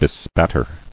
[be·spat·ter]